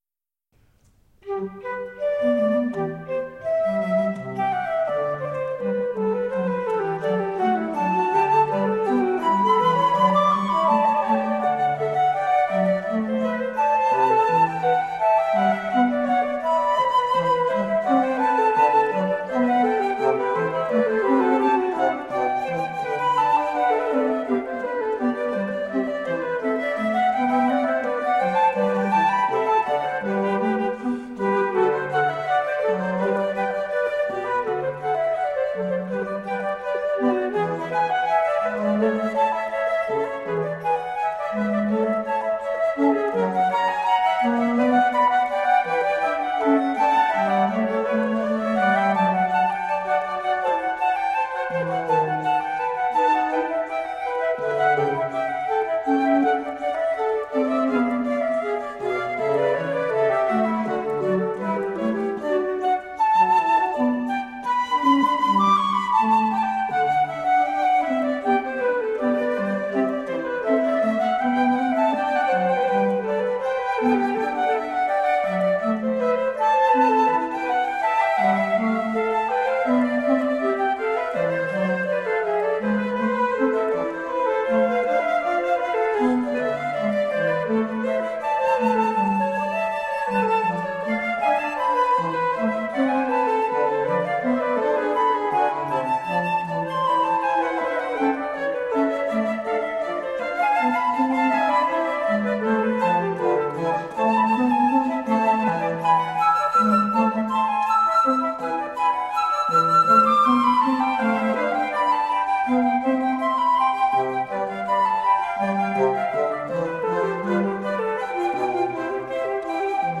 Flautists with finesse, intelligence and grooves.
flute ensemble
recorded live in Hakodate City Art Hall on 22 March 1999
Classical, Baroque, Impressionism, Instrumental
Flute